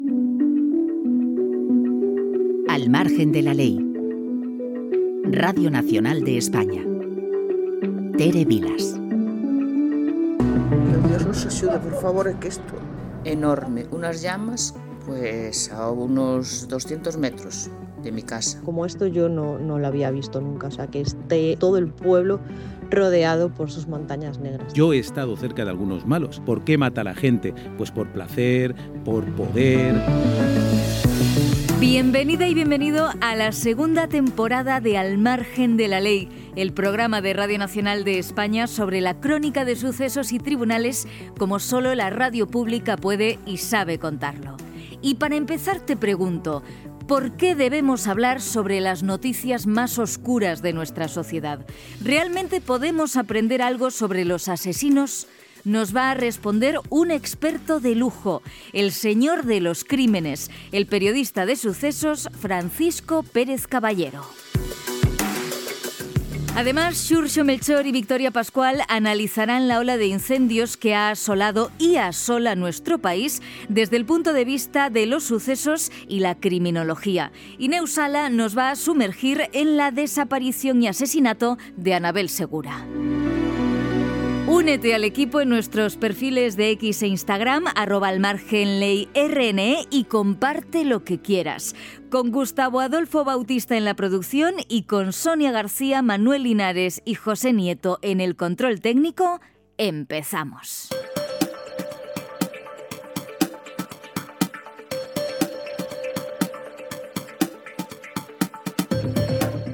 Careta del programa, benvinguda a la segona temporada del programa de successos i tribunals de RNE.
Informatiu
FM